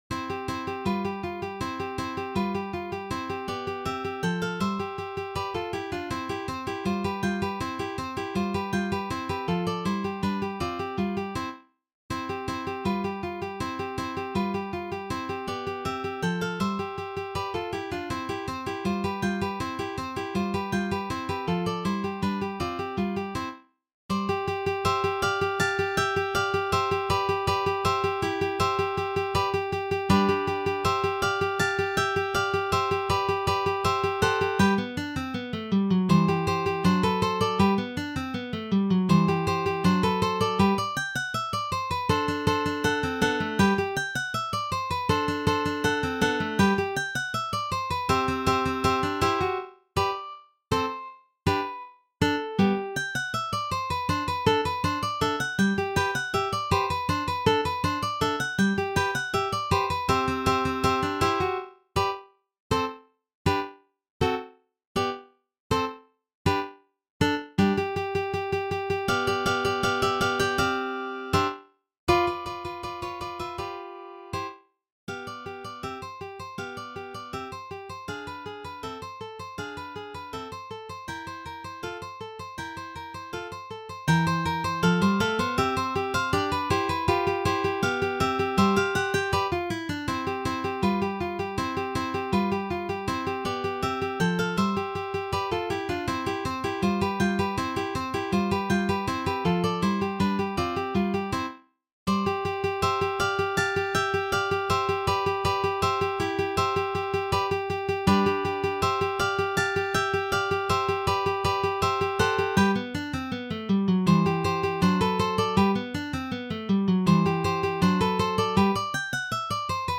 intermediate level arrangement for 3 guitars